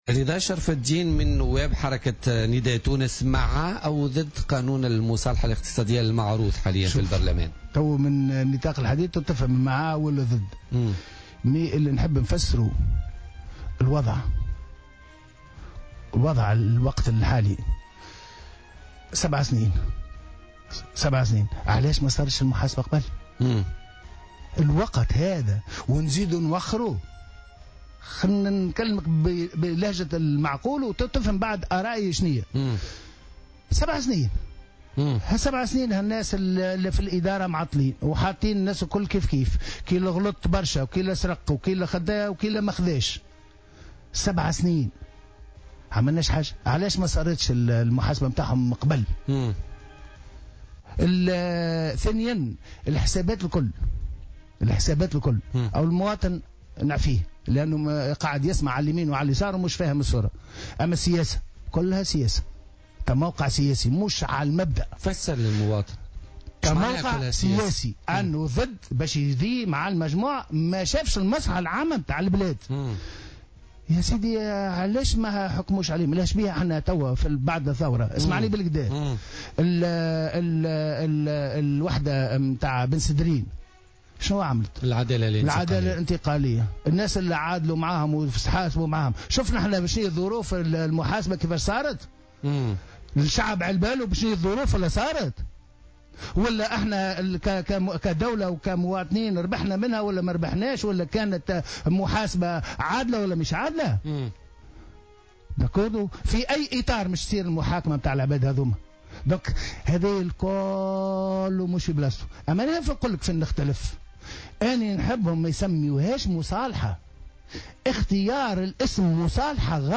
وأضاف شرف الدين ضيف "بوليتكا" اليوم الخميس 27 أفريل 2017، أن المعارضة تسوّق لقانون المصالحة الاقتصادية على أنه طريقة للإفلات من العقاب دون محاسبة، وذلك لمصلحة سياسية، متابعا "ليتهم يقدّمون بعض الحلول".